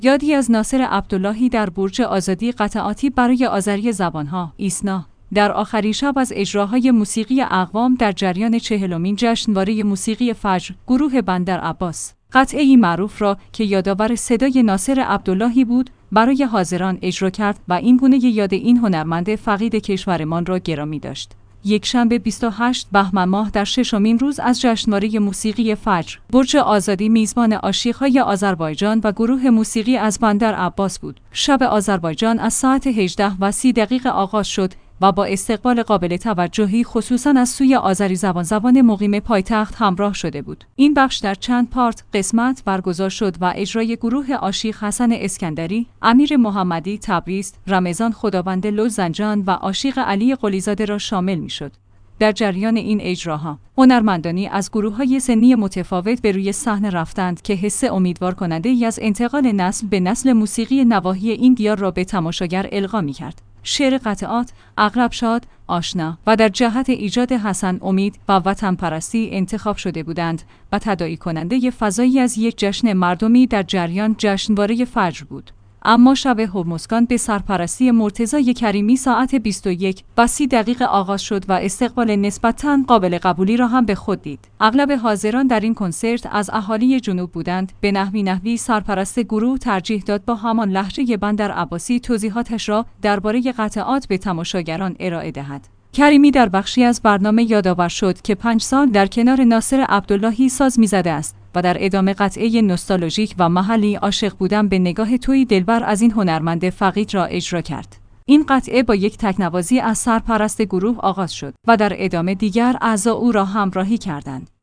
ایسنا/ در آخرین شب از اجراهای موسیقی اقوام در جریان چهلمین جشنواره موسیقی فجر، گروه بندرعباس، قطعه‌ای معروف را که یادآور صدای ناصر عبداللهی بود، برای حاضران اجرا کرد و این‌گونه یاد این هنرمند فقید کشورمان را گرامی داشت.